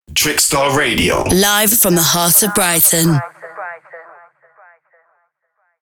TS - Positioner 2 (Mixed) DRY
Category: Radio   Right: Personal